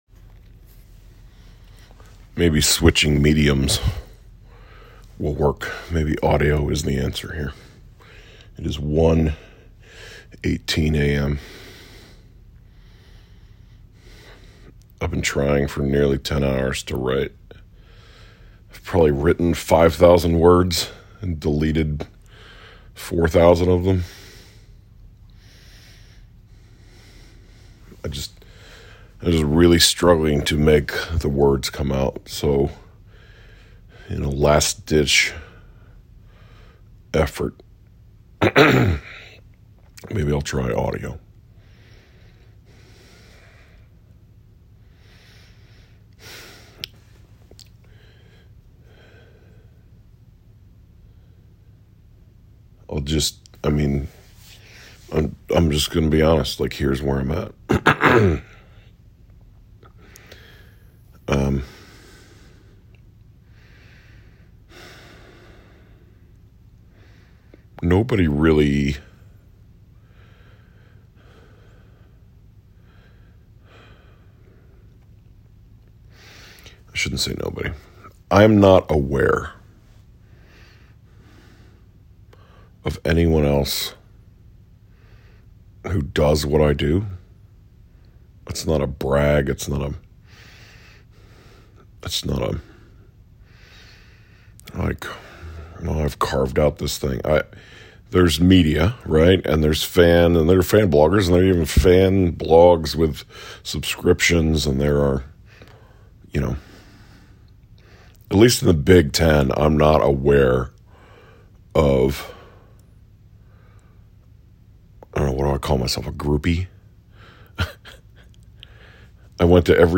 This is basically one long From The Stands from my hotel room at 1:18 am after trying to write (and failing) for nine hours. No other way to say it: Loyola + Houston has me completely devastated.